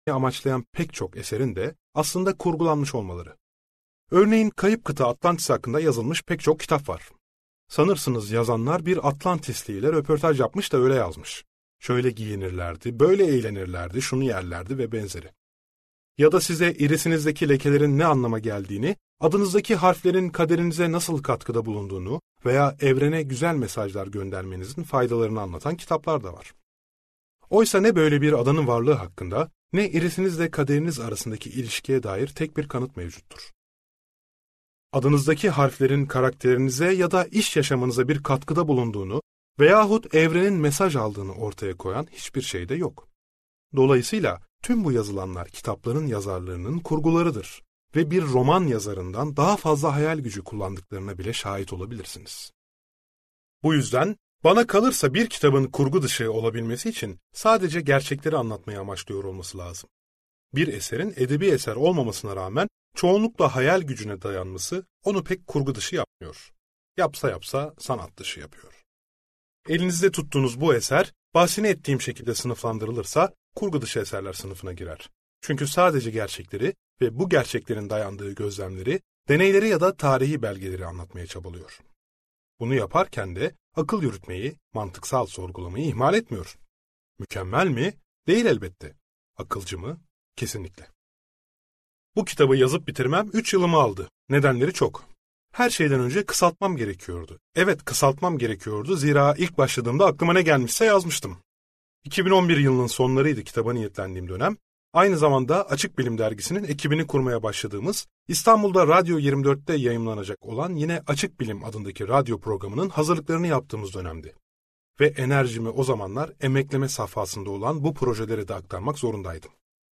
Astrolojinin Bilimle İmtihanı - Seslenen Kitap